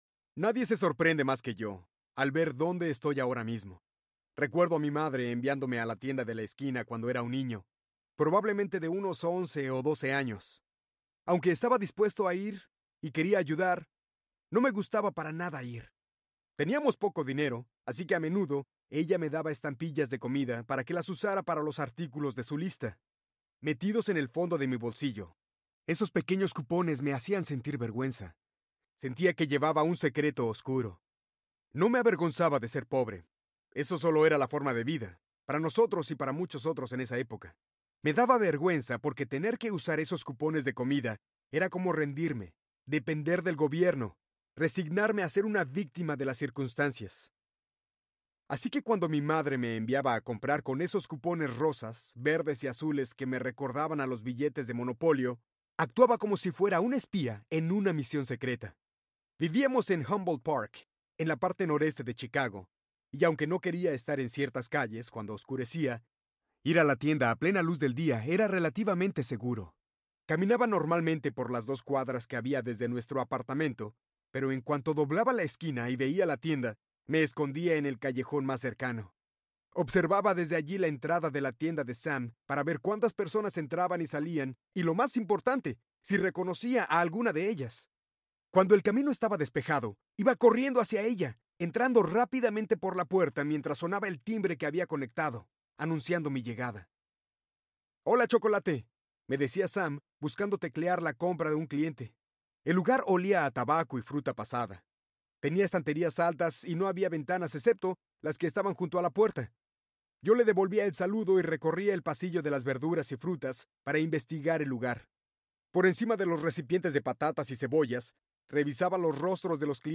Muévase a más Audiobook
Narrator
6.1 Hrs. – Unabridged